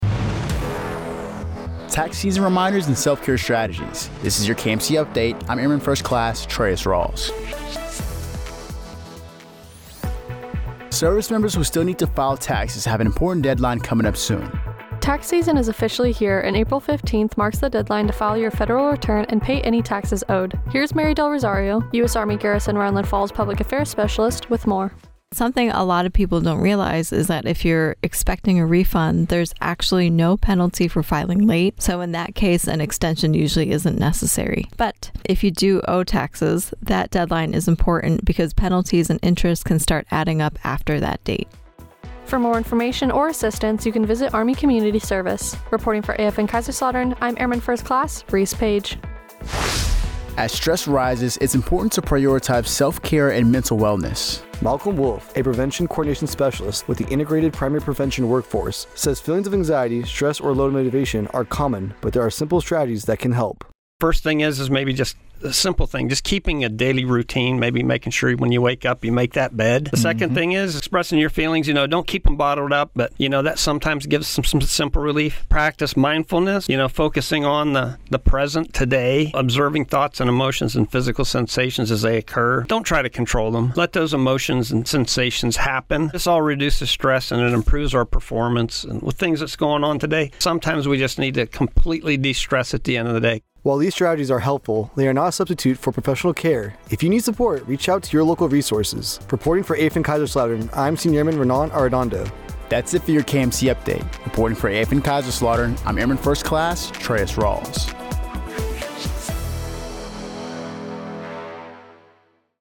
AFN Kaiserslautern